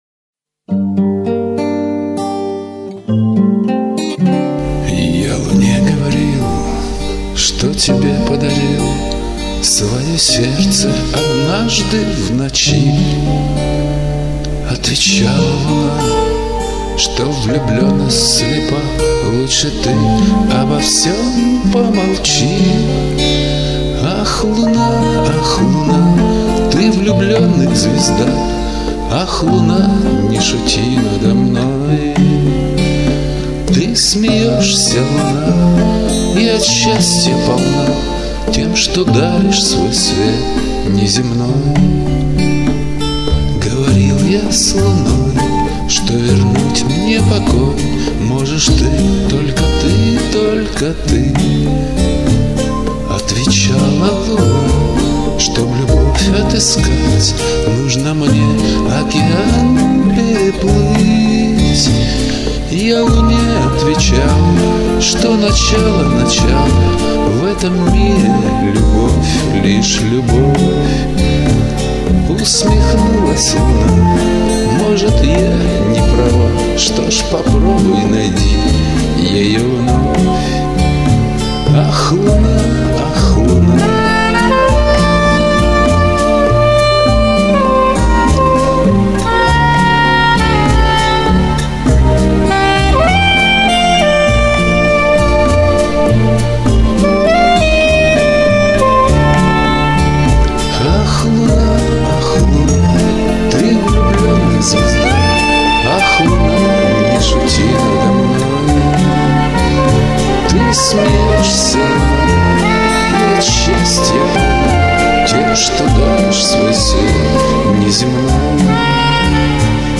Главное, что исполнение чистенькое, без "лажи" у обоих. 3:3